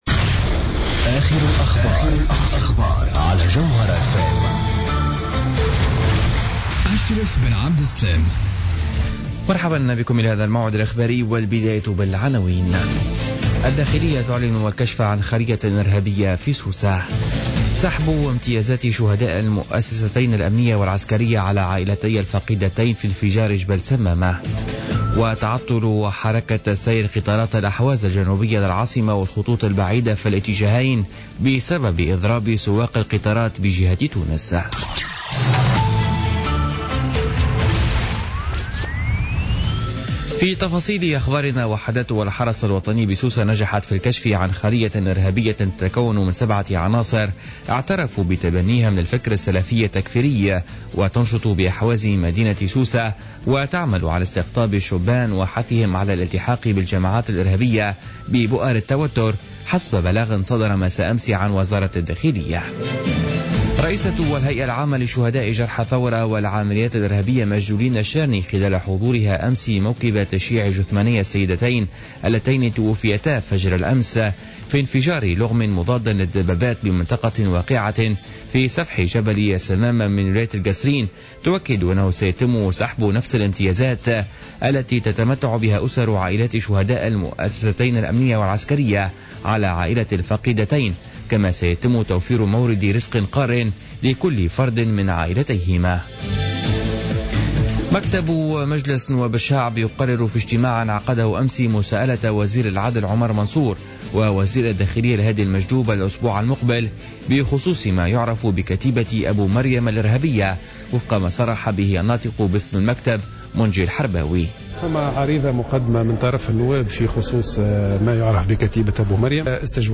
نشرة أخبار منتصف الليل ليوم الثلاثاء 30 ماي 2016